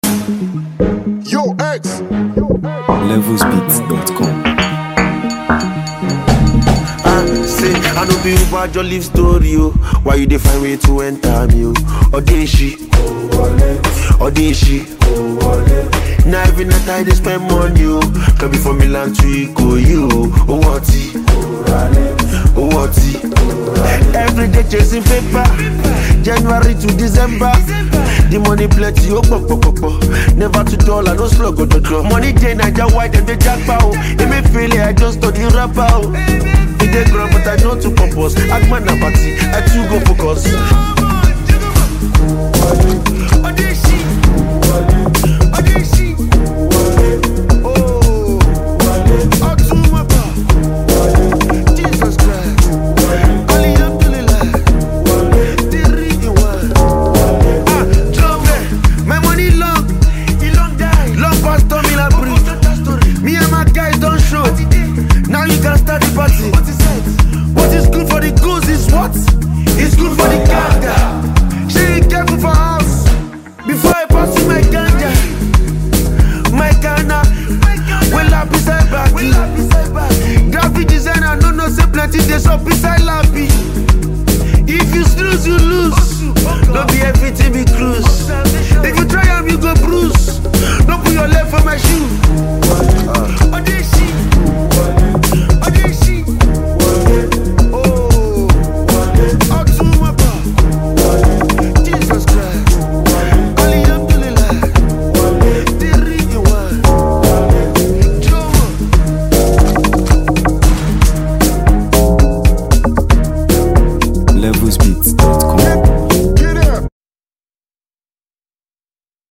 Nigeria Music 2025 1:59